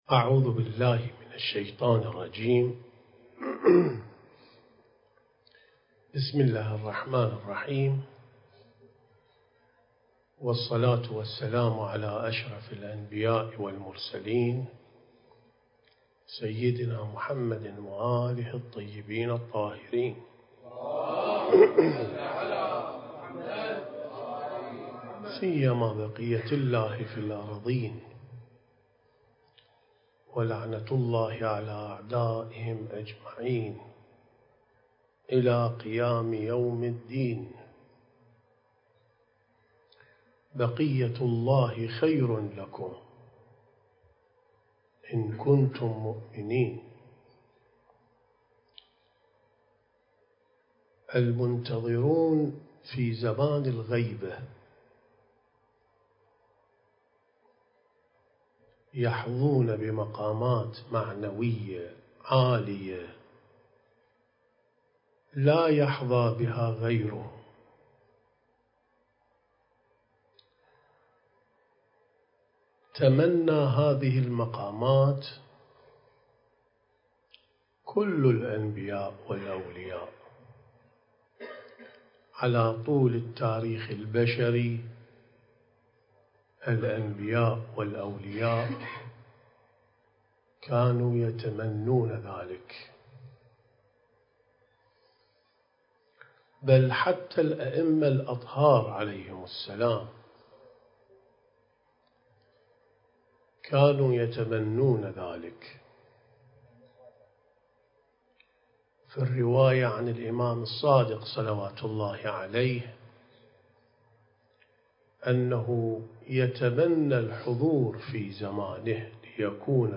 سلسة محاضرات أين المنتظرون (٩)